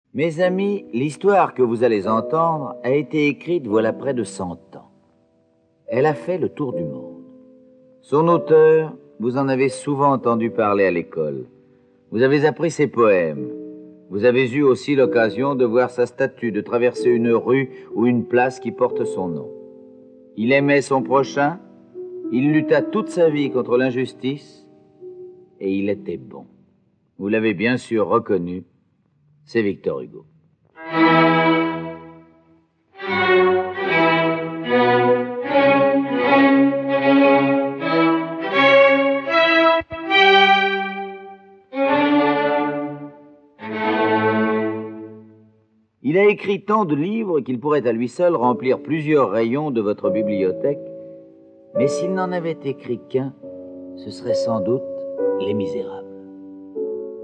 Diffusion distribution ebook et livre audio - Catalogue livres numériques
enregistrement original de 1954